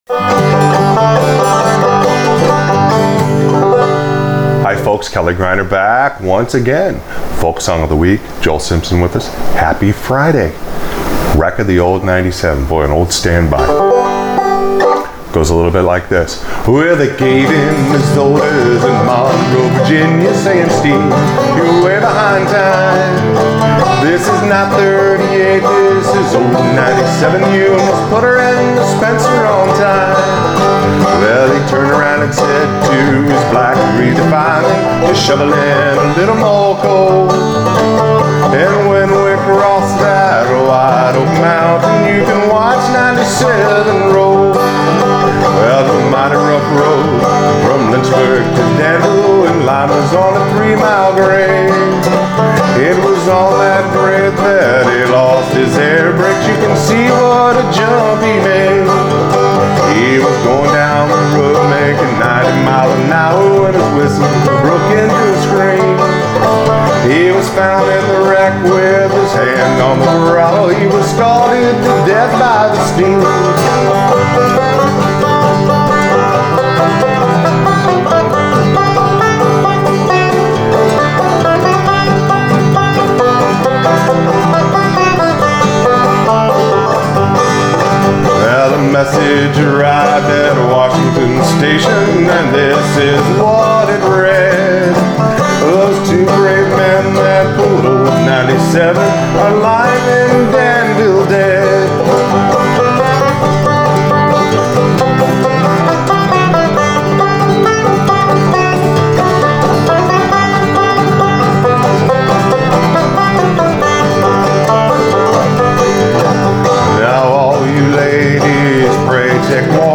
Folk Song Of The Week – Wreck Of The Old 97 – Accompaniment for Frailing Banjo